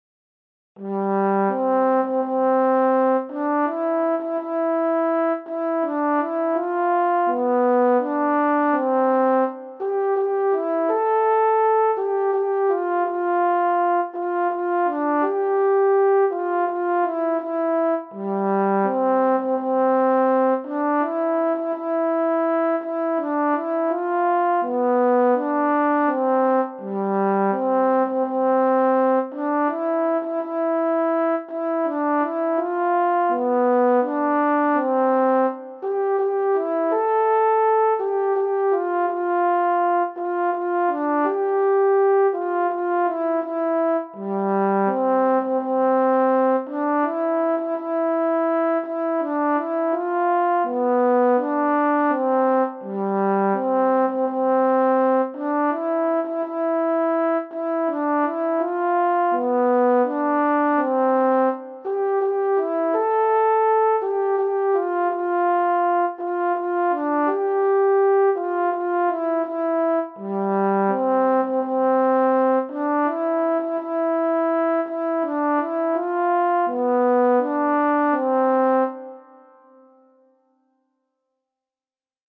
für Posaune solo, Noten und Text als pdf, Audio als mp3